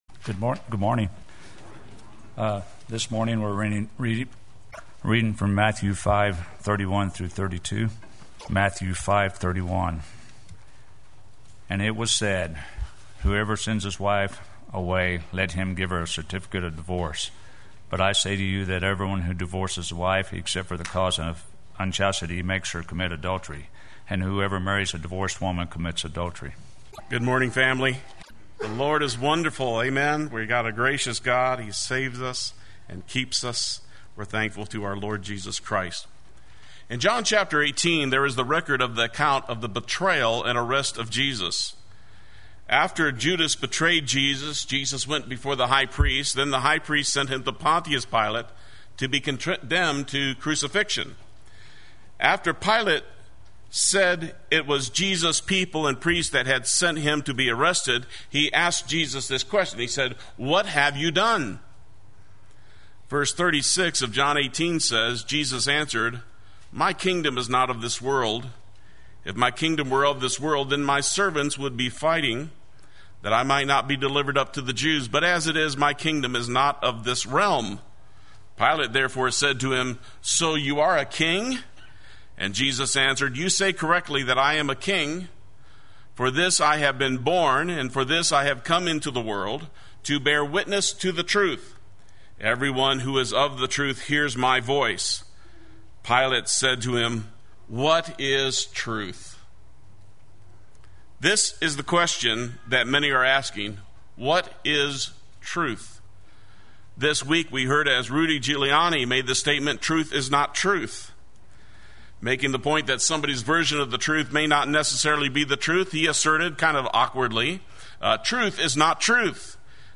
Play Sermon Get HCF Teaching Automatically.
“But I Say to You” III Sunday Worship